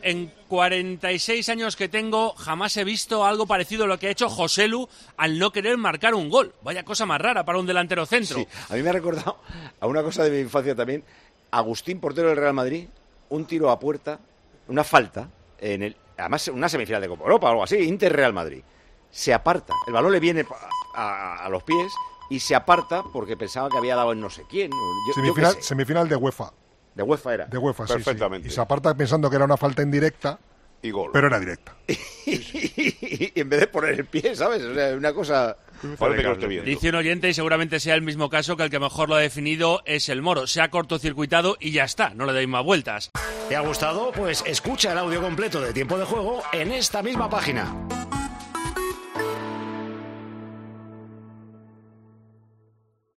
Los oyentes de Tiempo de Juego hablan también de la jugada surrealista del Cádiz-Real Madrid